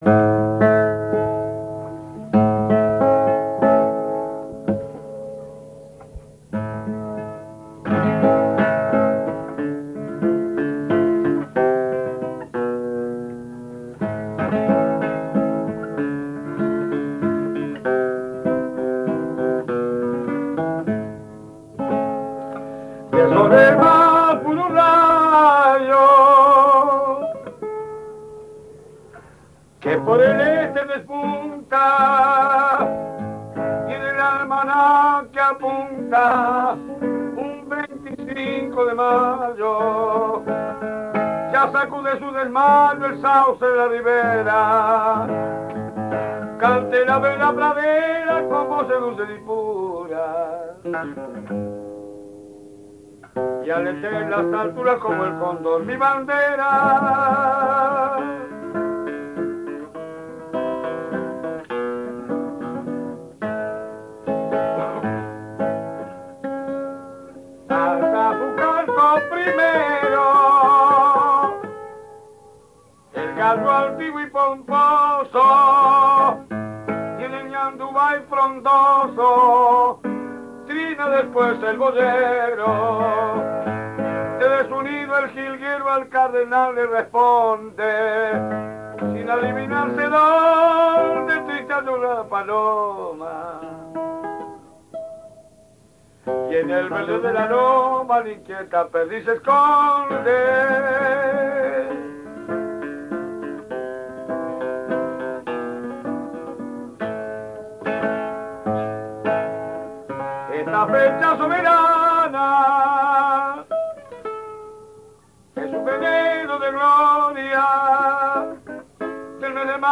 canto y guitarra
Formato original de la grabación: cinta magnética a 9,5 cm/s